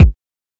• 2000s Subtle Reverb Kick Single Hit E Key 35.wav
Royality free steel kick drum sound tuned to the E note. Loudest frequency: 226Hz